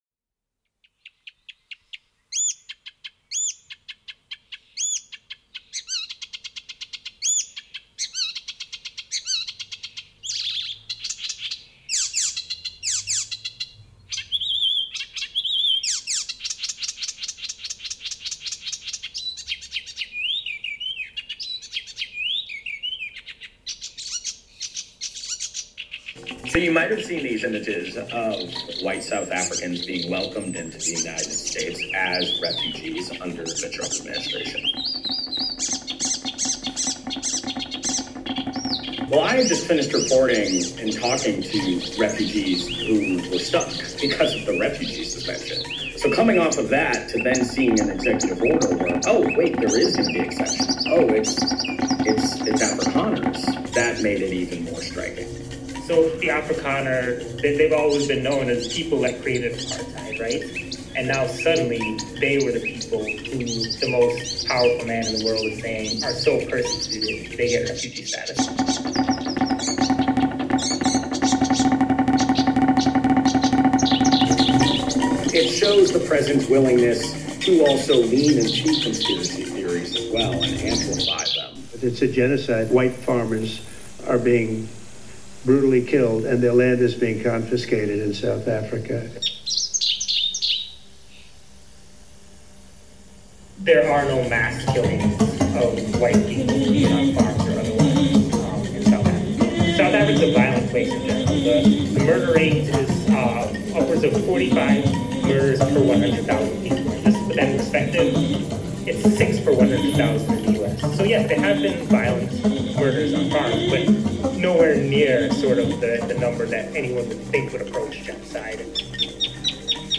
Uraufführung